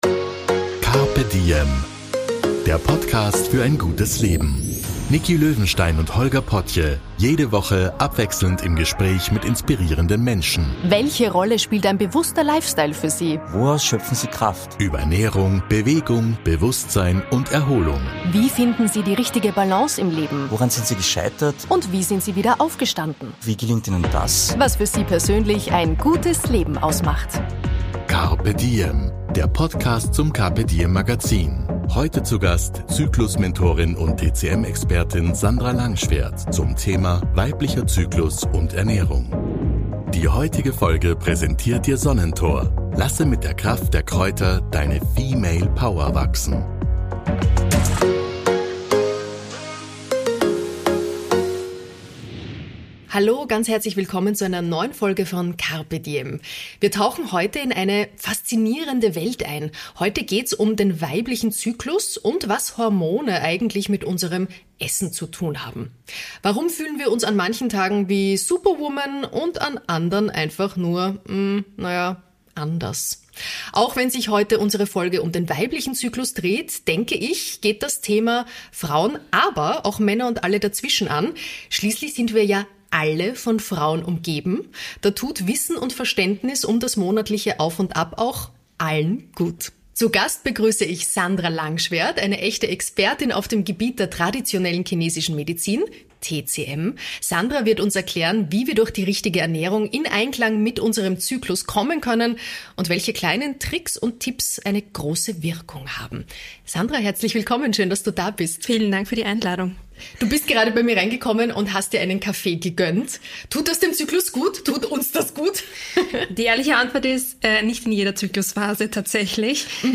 Hormone und Ernährung bestimmen unsere Alltag. In diesem Interview aus dem carpe diem Podcast erzähle ich einiges dazu.